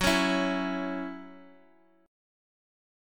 C#mbb5/F# chord